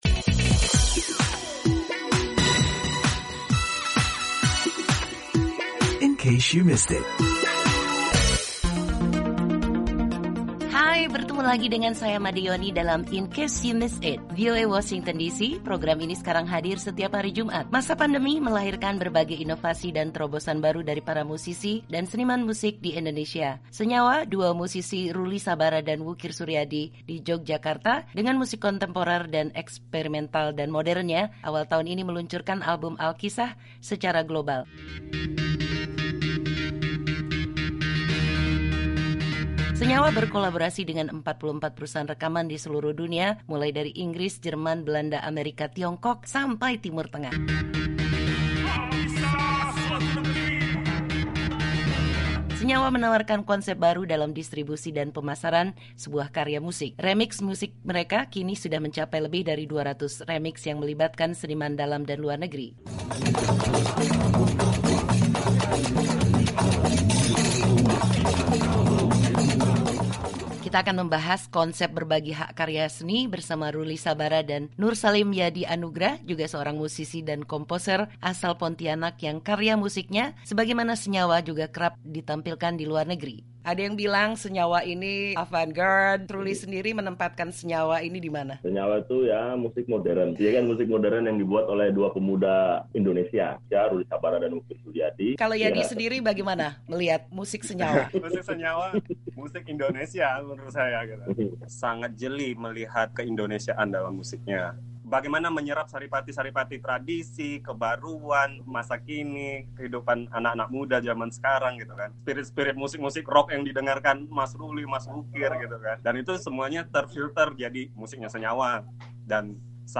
berbincang dengan Senyawa